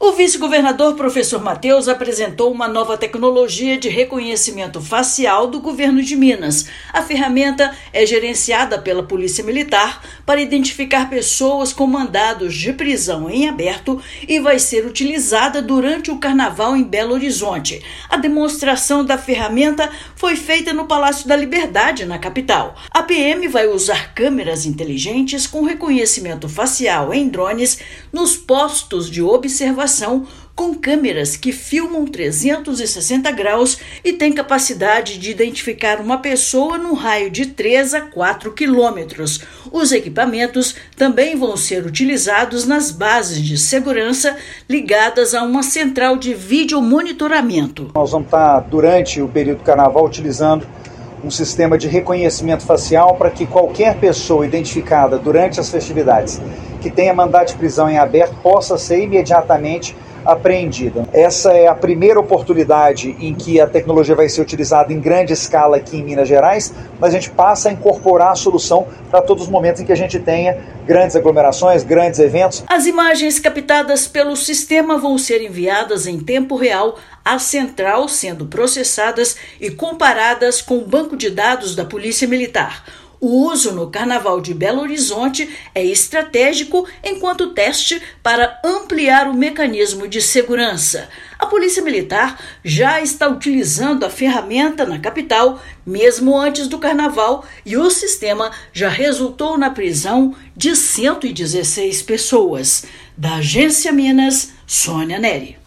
[RÁDIO] Governo de Minas utilizará nova tecnologia de reconhecimento facial no Carnaval da capital mineira
Ferramenta da Polícia Militar será empregada para identificar pessoas com mandado de prisão em aberto. Ouça matéria de rádio.